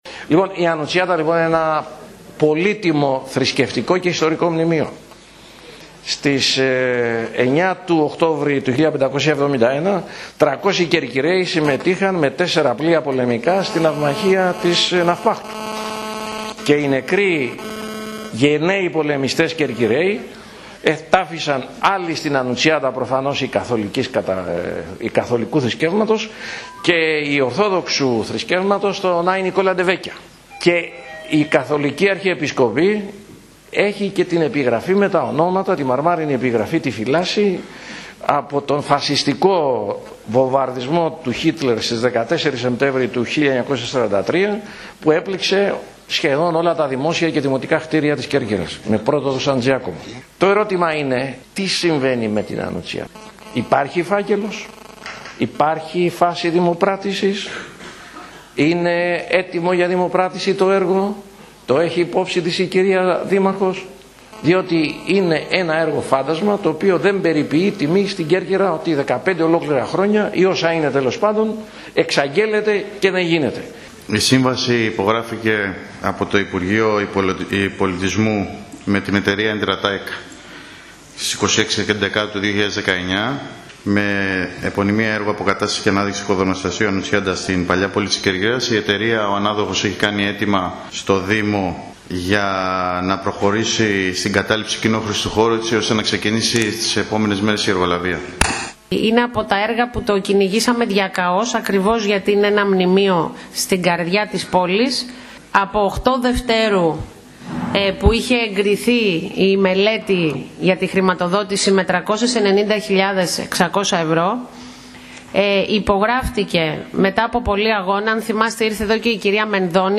Σύντομα αναμένεται να ξεκινήσουν οι παρεμβάσεις στην Ανουτσιάτα, ανέφερε ο Αντιδήμαρχος Τεχνικών Υπηρεσιών Νίκος Καλόγερος, απαντώντας σε ερώτηση του Γιώργου Καλούδη, κατά τη διάρκεια της χθεσινής συνεδρίασης του Δημοτικού Συμβουλίου Κεντρικής Κέρκυρας. Όπως τόνισε η Δήμαρχος Μερόπη Υδραίου αν και ο εργολάβος έχει εγκατασταθεί, υπάρχει ζήτημα με την απομάκρυνση ενός από τους παλιούς ενοικιαστές του χώρου.